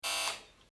wrong.mp3